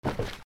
カバンを落とす(mix用素材)
/ J｜フォーリー(布ずれ・動作) / J-10 ｜転ぶ　落ちる
服の上